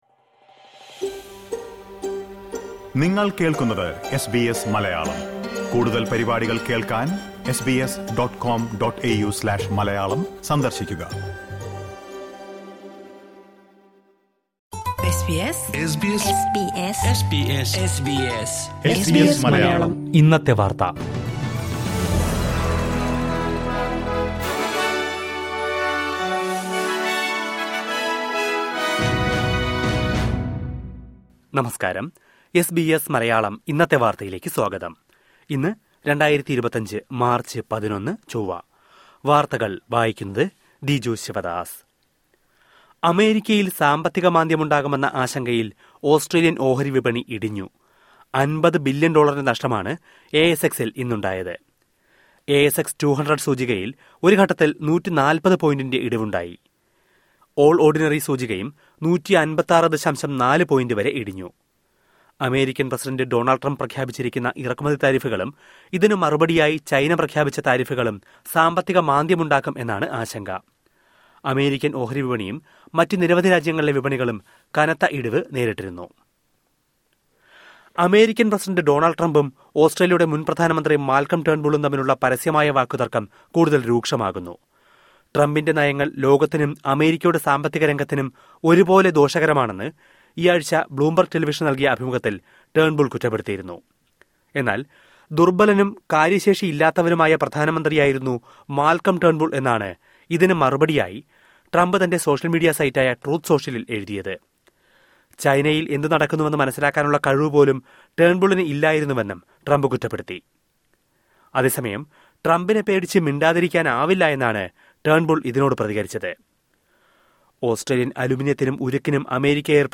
2025 മാര്‍ച്ച് 11ലെ ഓസ്‌ട്രേലിയയിലെ ഏറ്റവും പ്രധാന വാര്‍ത്തകള്‍ കേള്‍ക്കാം...